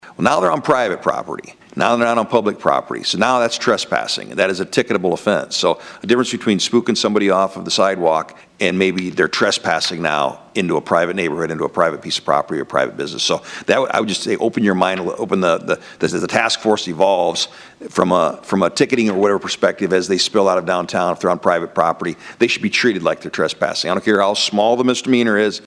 COUNCILMAN RICK BERTRAND THANKED HIM FOR WHAT THE DEPARTMENT AND TASK FORCE IS DOING, BUT IS CONCERNED ABOUT WHAT HAPPENS WHEN THE WEATHER WARMS UP IN THE SPRING, AND HOMELESS COME ONTO PRIVATE INSTEAD OF PUBLIC PROPERTIES: